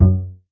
bass.ogg